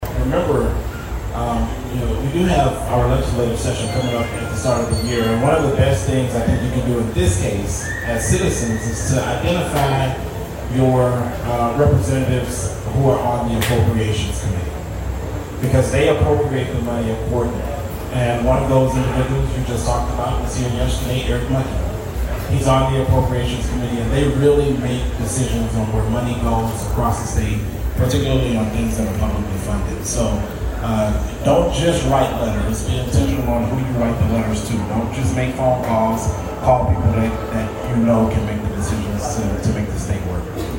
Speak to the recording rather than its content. in the town hall the Democrat Party hosted on Saturday